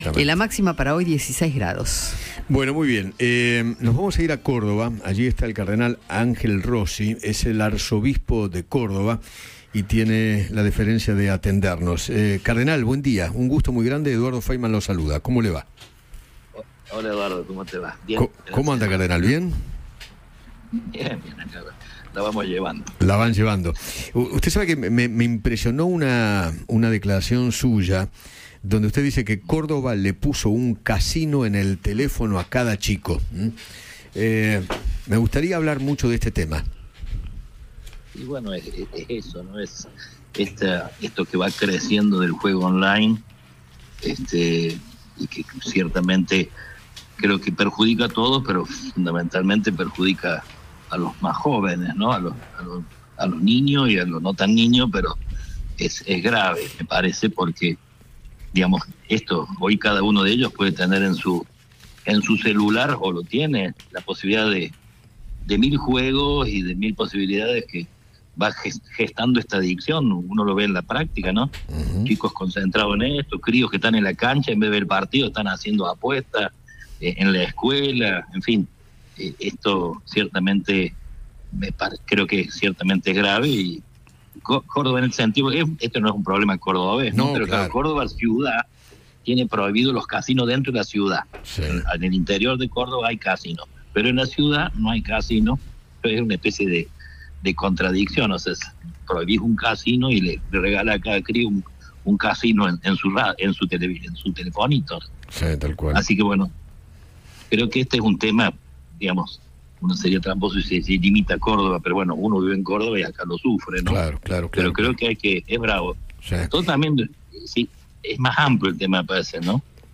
Ángel Rossi, arzobispo de Córdoba, conversó con Eduardo Feinmann sobre la realidad social y alertó por el crecimiento del juego online.